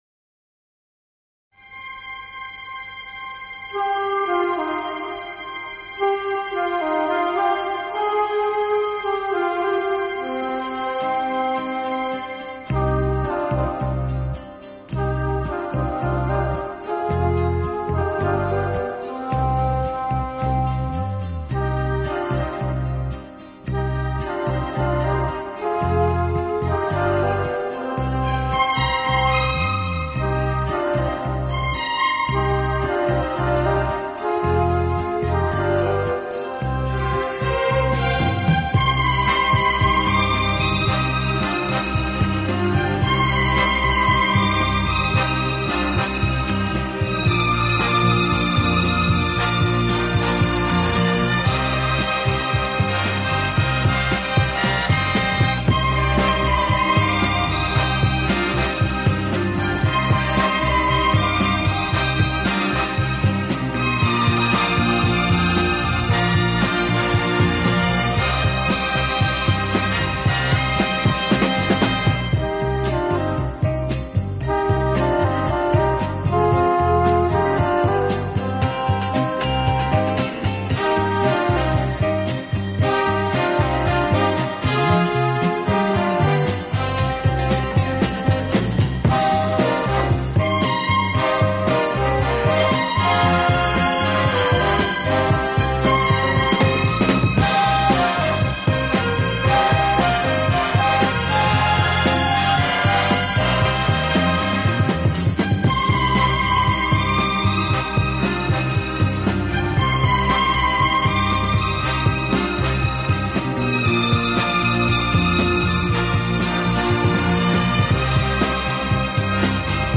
音乐类型: Pop/Instrumental